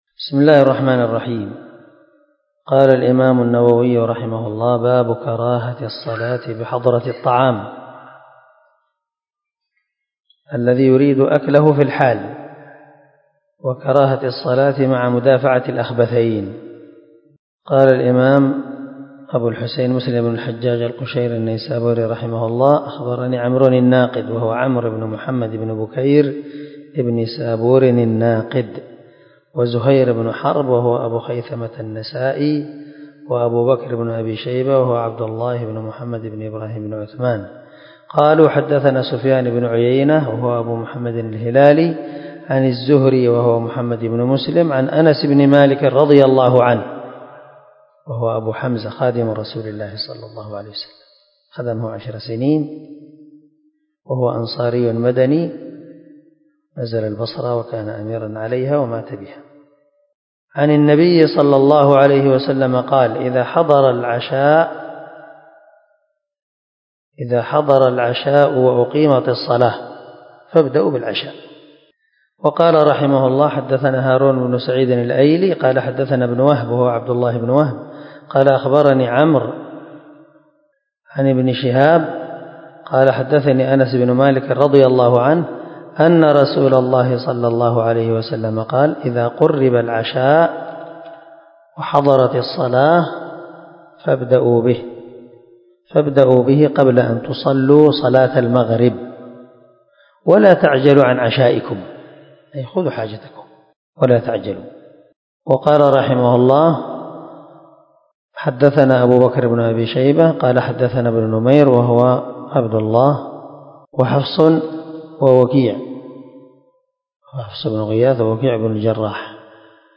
350الدرس 22 من شرح كتاب المساجد ومواضع الصلاة حديث رقم ( 557 - 559 ) من صحيح مسلم